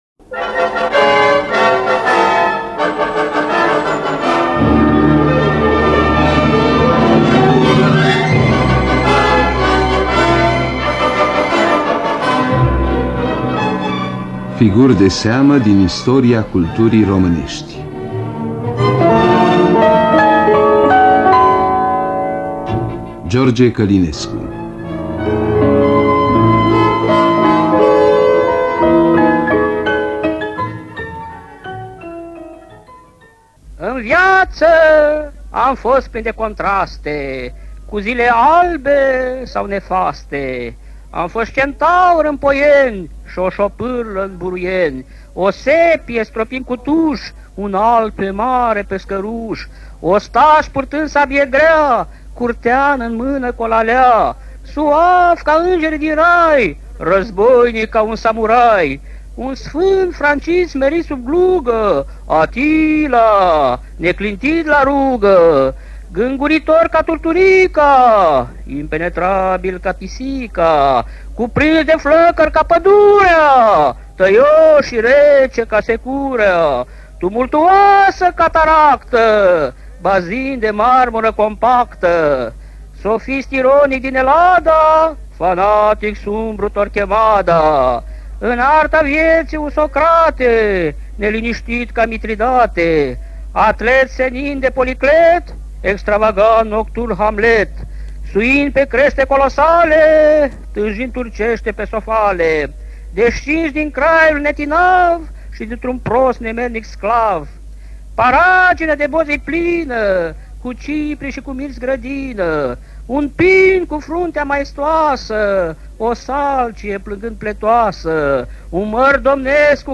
Scenariu radiofonic de Doina Papp și Magdalena Boiangiu.
Înregistrare din anul 1975.